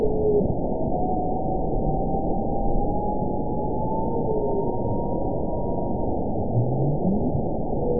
event 922176 date 12/27/24 time 23:13:07 GMT (11 months, 2 weeks ago) score 9.28 location TSS-AB04 detected by nrw target species NRW annotations +NRW Spectrogram: Frequency (kHz) vs. Time (s) audio not available .wav